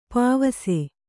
♪ pāvase